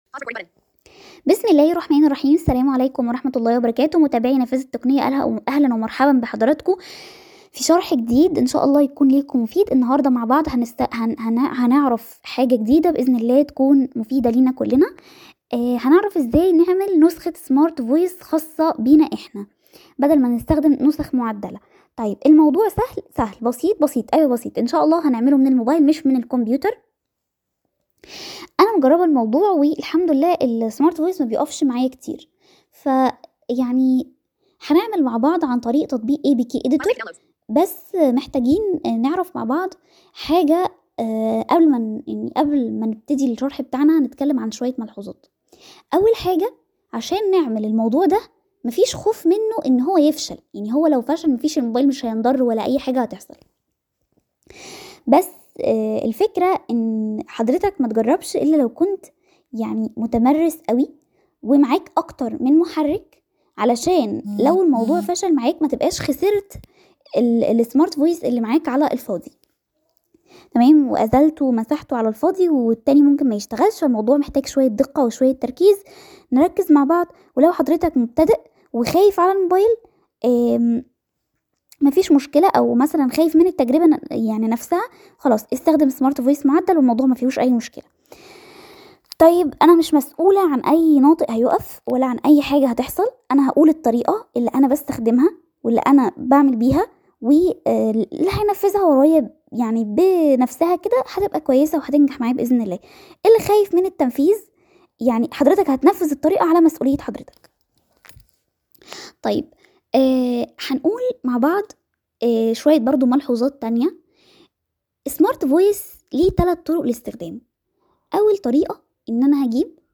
الشرح الصوتي: